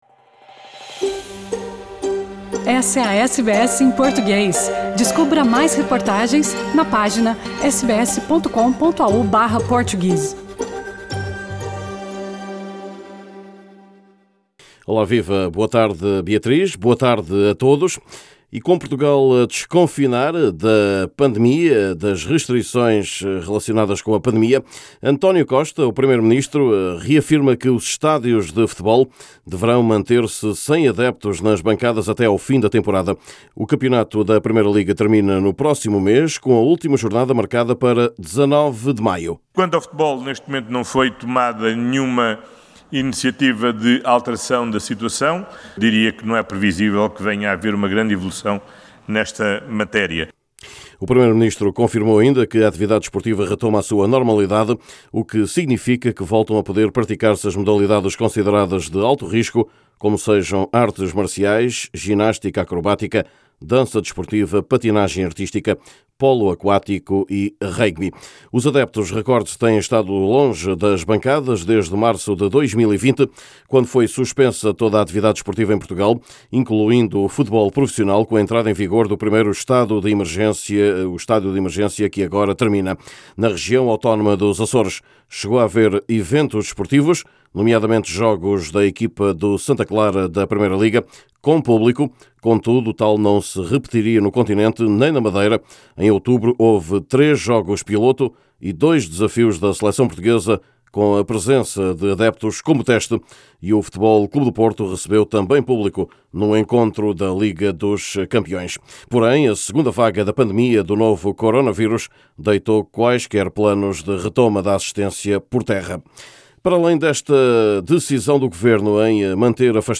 Nesse boletim esportivo: Executivo de Lisboa mantém o público afastado dos recintos de futebol. Futebol português a “ferro e fogo” na ponta final da temporada.